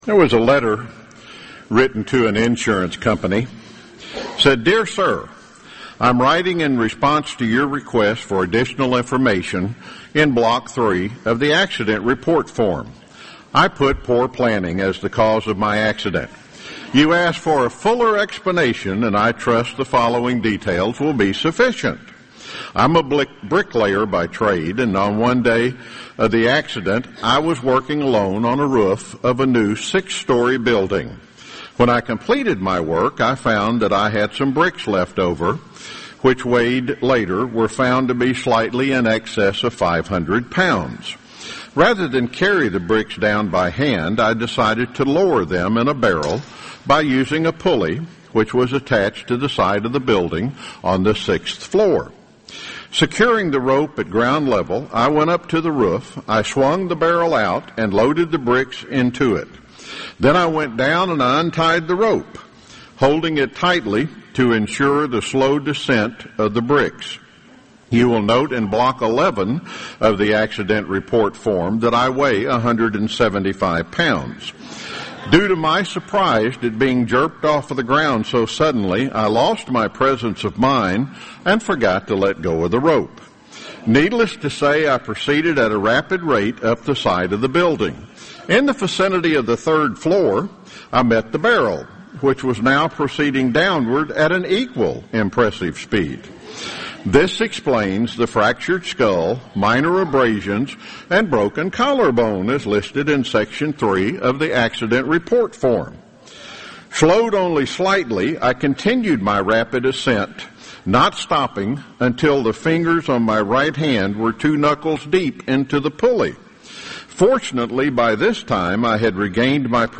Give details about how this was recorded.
This sermon was given at the Jekyll Island, Georgia 2013 Feast site.